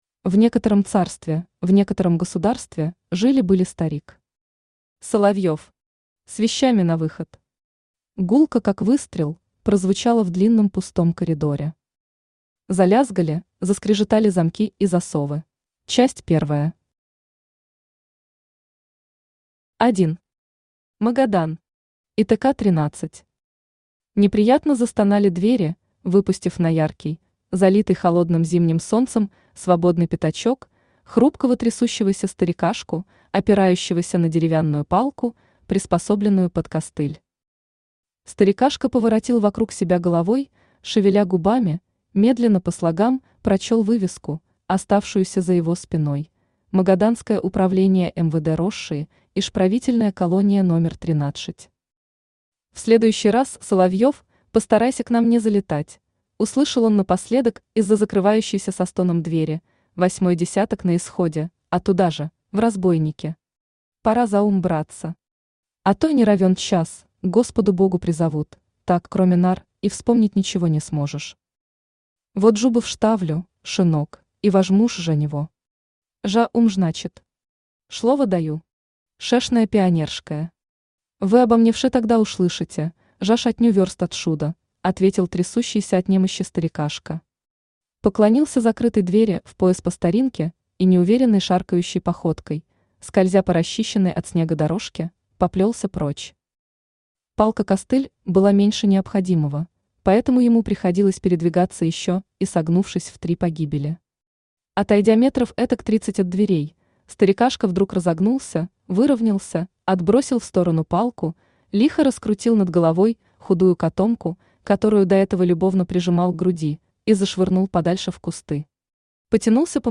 Аудиокнига Баба Яга против Агента 00 | Библиотека аудиокниг
Aудиокнига Баба Яга против Агента 00 Автор Сергей Алексеевич Глазков Читает аудиокнигу Авточтец ЛитРес.